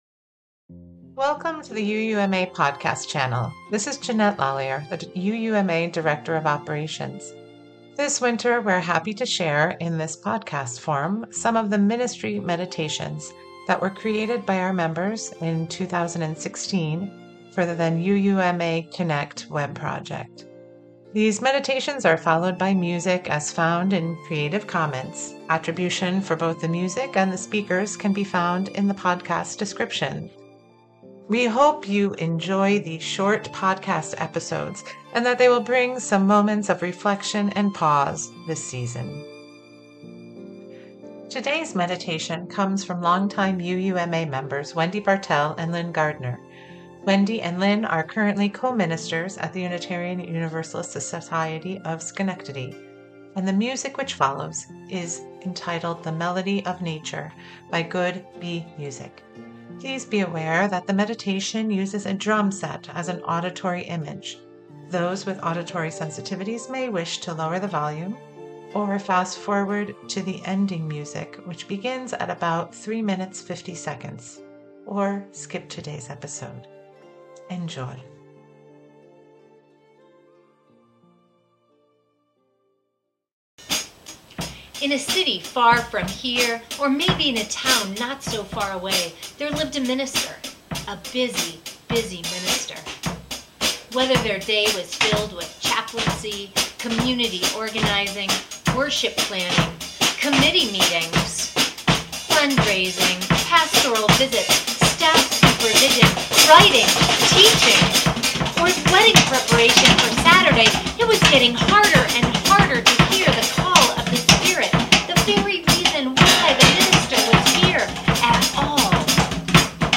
These meditations are followed by music as found in creative commons.